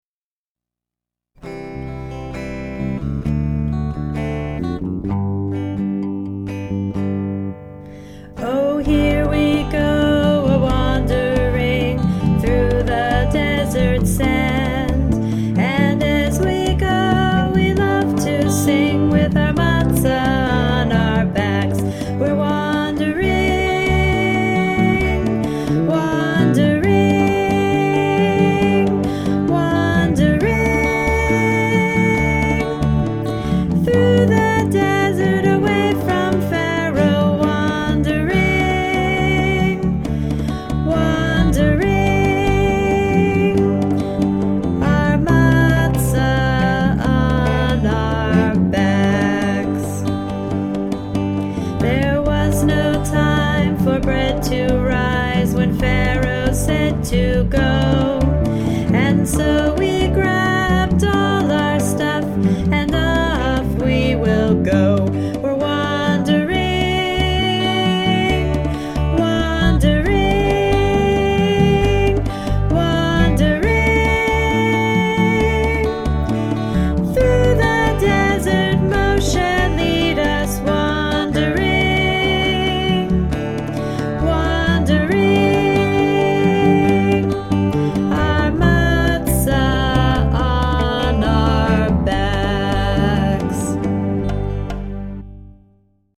we are preschool teachers, not professional singers )